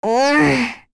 Rehartna-Vox_upset2_kr.wav